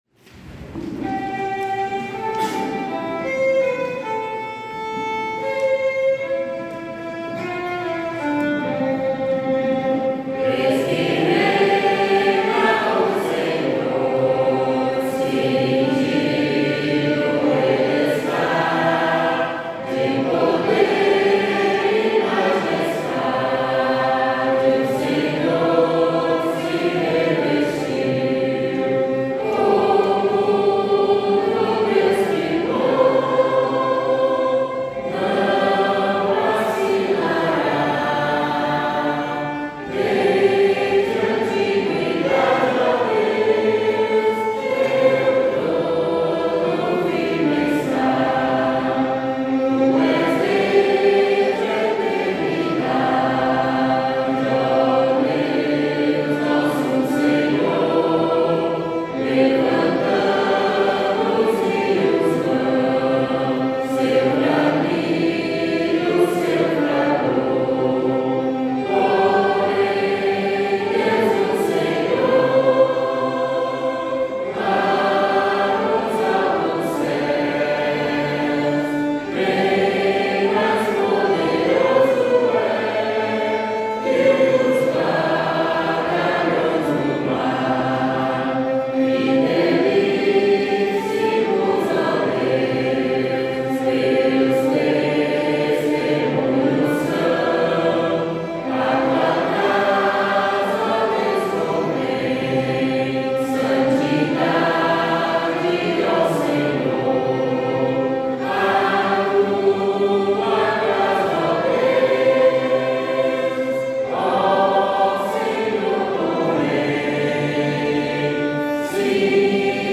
salmo_93B_cantado.mp3